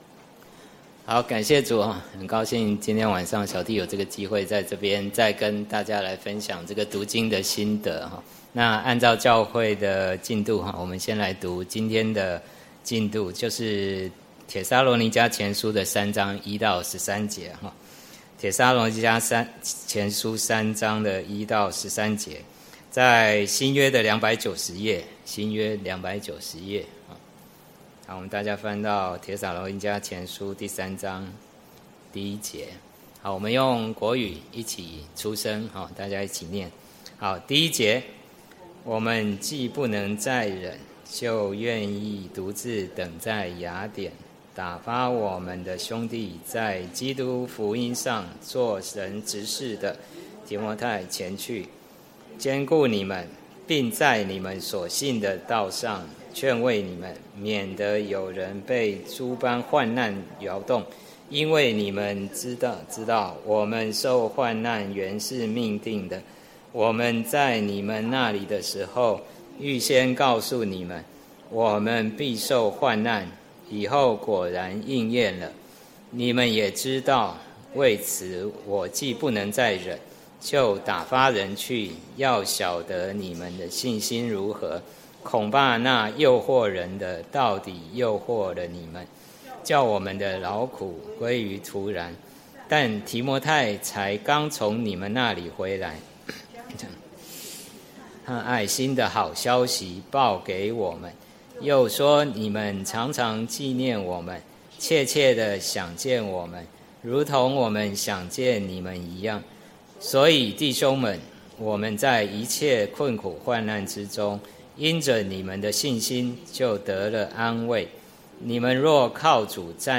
有葉無果-講道錄音